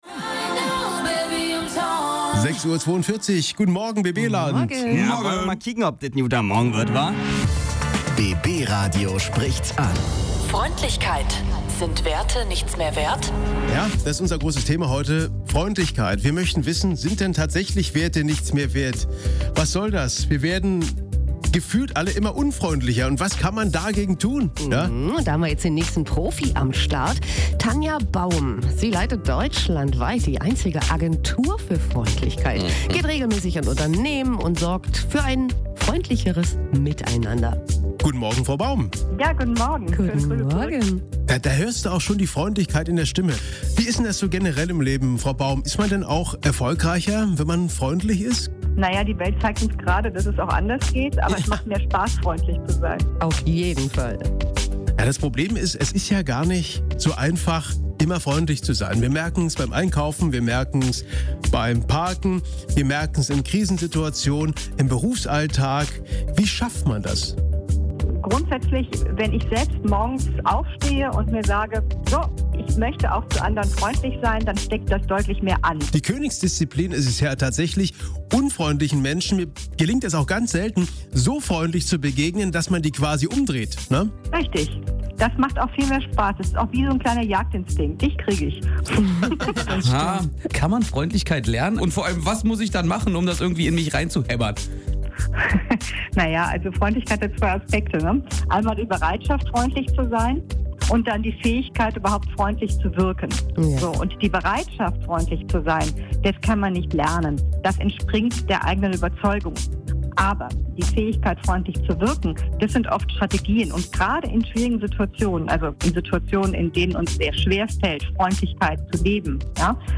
Zum Radiointerview